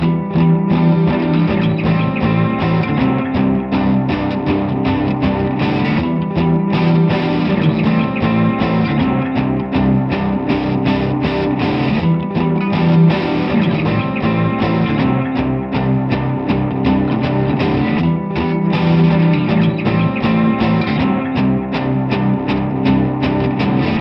描述：带有3个吉他循环的小曲子
Tag: 80 bpm Pop Loops Guitar Electric Loops 4.04 MB wav Key : A